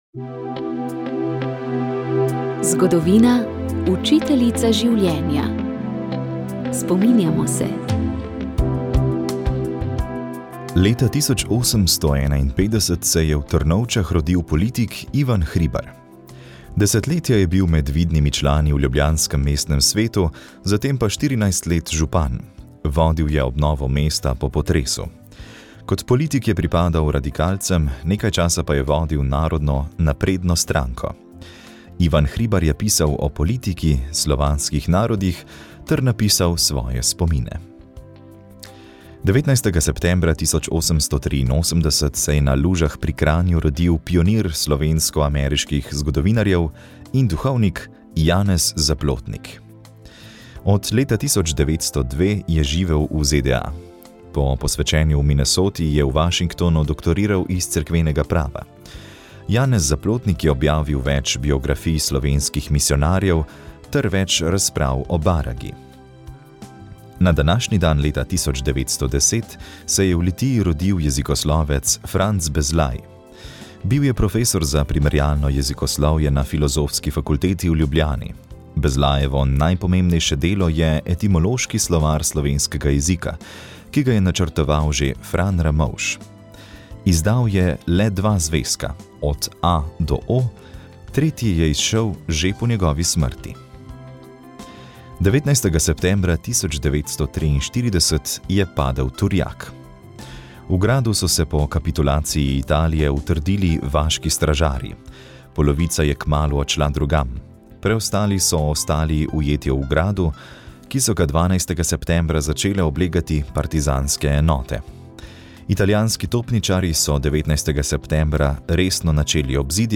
V tokratni oddaji je bil naš gost dr. Žiga Turk. Govorili smo o bližajočih se evropskih volitvah, razmerah na slovenskem političnem parketu in referendumskih aktivnostih.